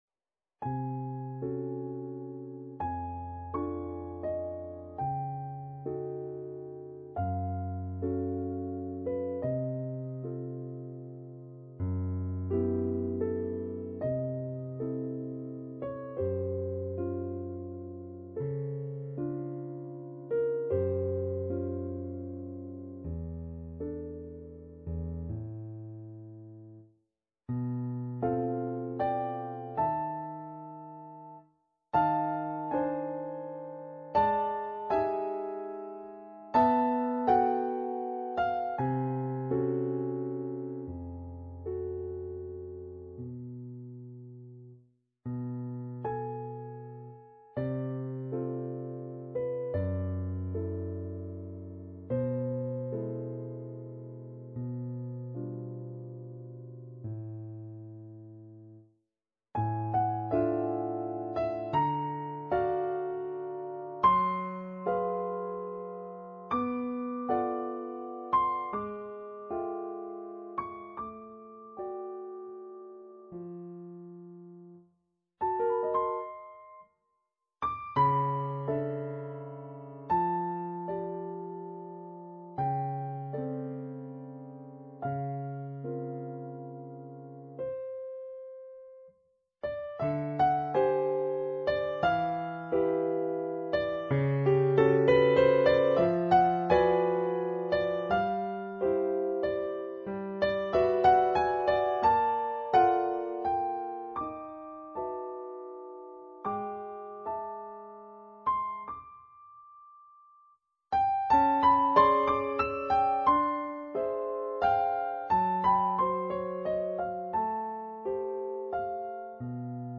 Piano Piano music page